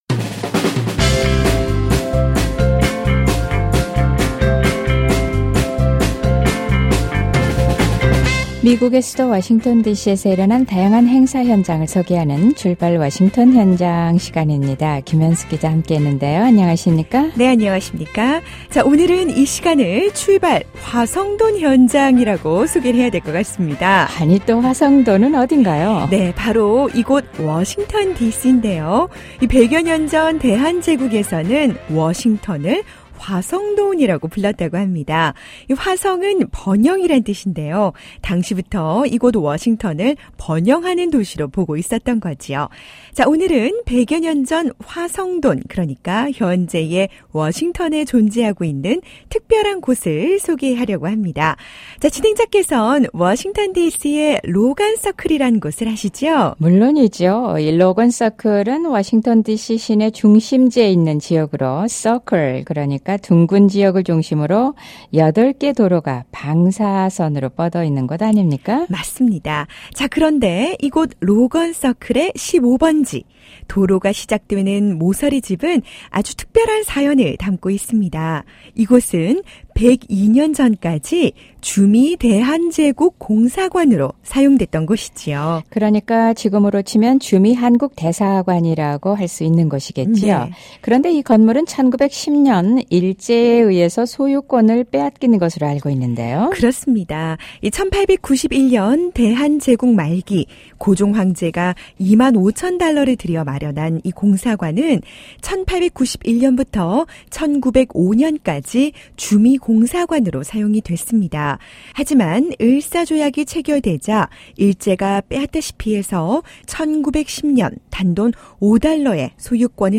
주미대한제국공사관의 역사적 가치와 활용방안에 대해 논의했던 세미나 현장을 소개합니다.